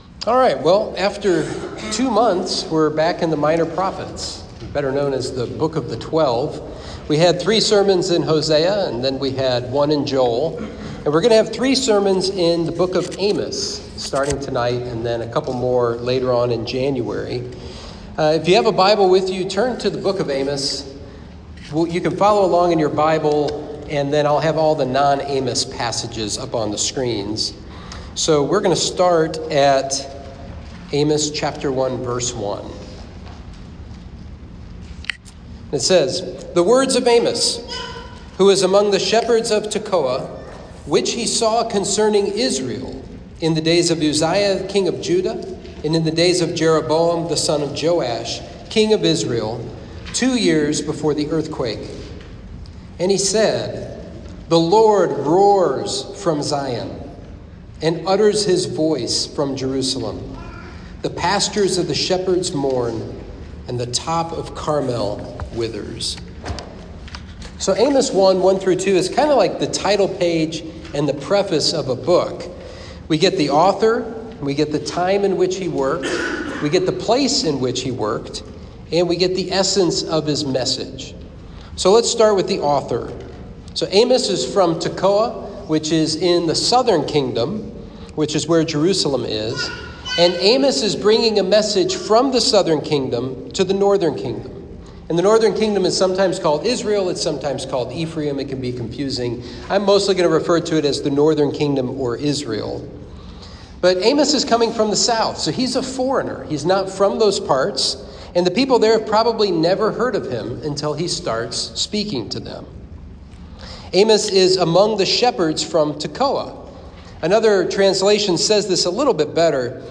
Sermon podcast for Trinity Christian Fellowship (TCF) in Lexington, KY